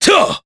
Nicx-Vox_Attack3_jp.wav